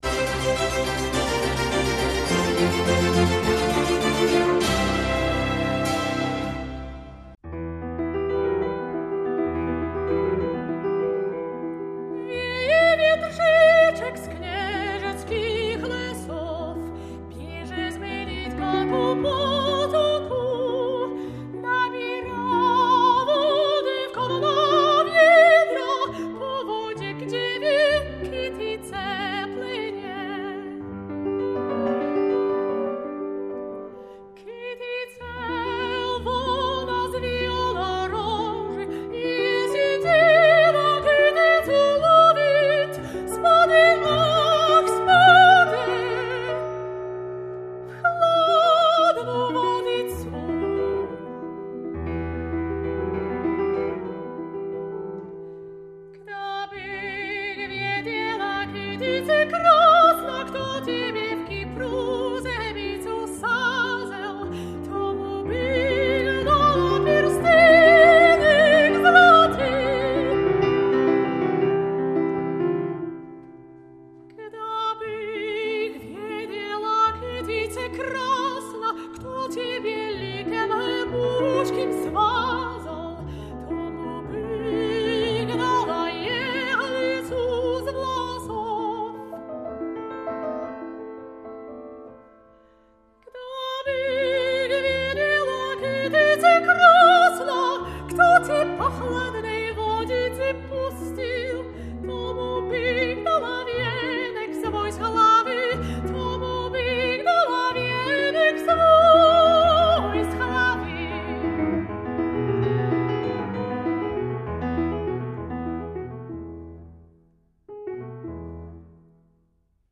śpiewaczka
pianista